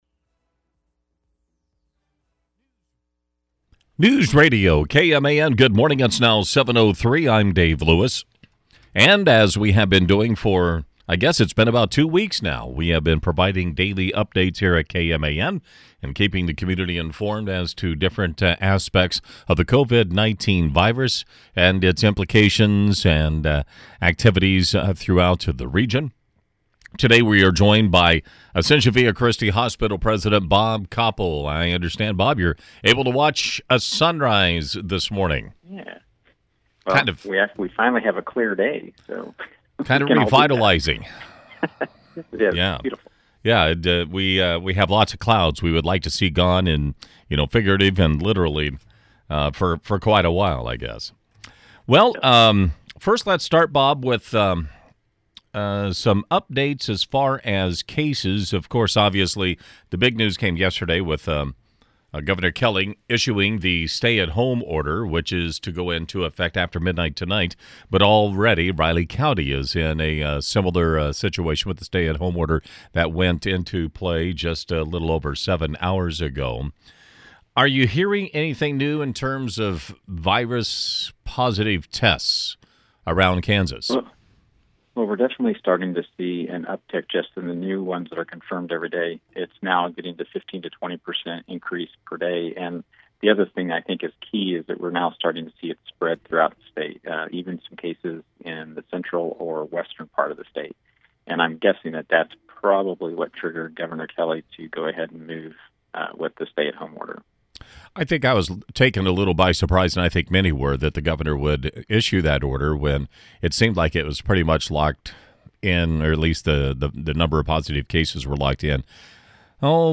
Daily updates can be heard live on KMAN at 7am and 5pm.